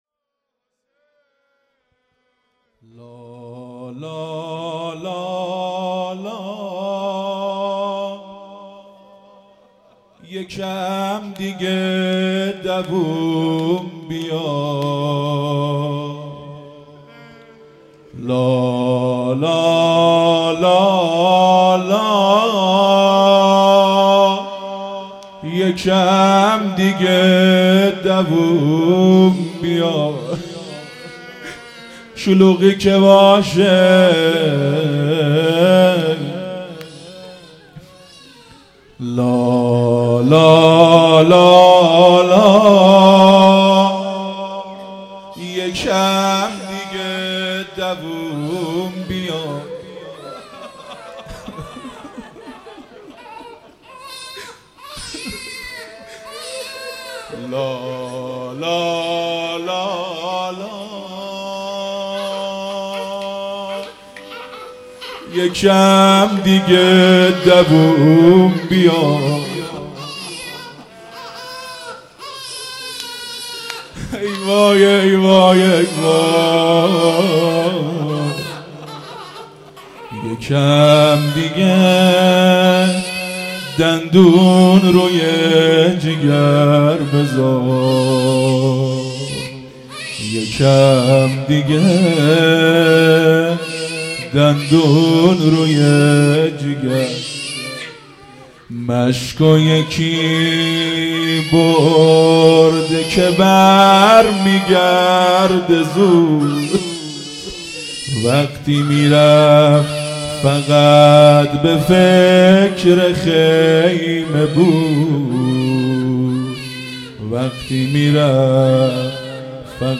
روضه | لالالالا یکم دیگه دوام بیار مداح
محرم1442_شب هفتم